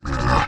Minecraft Version Minecraft Version 1.21.4 Latest Release | Latest Snapshot 1.21.4 / assets / minecraft / sounds / mob / camel / ambient2.ogg Compare With Compare With Latest Release | Latest Snapshot